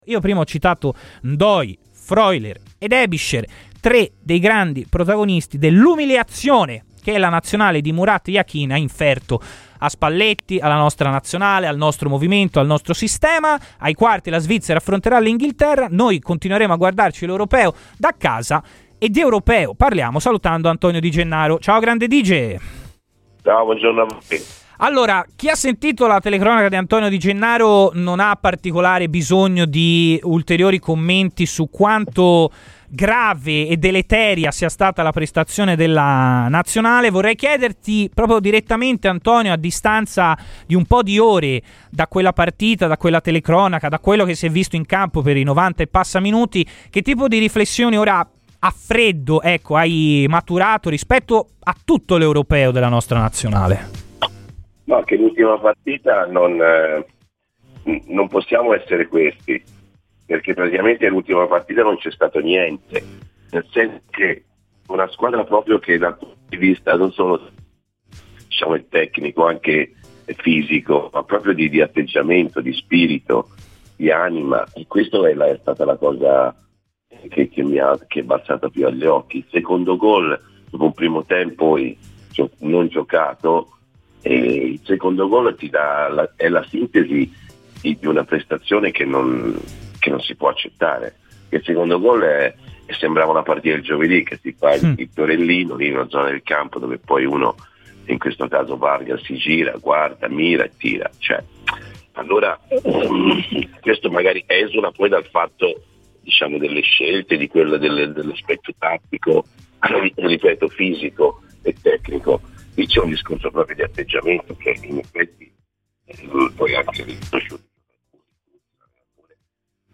Nella mattinata di Radio FirenzeViola spazio ad Antonio Di Gennaro, ex Fiorentina e attualmente commentatore sportivo per RaiSport ha parlato.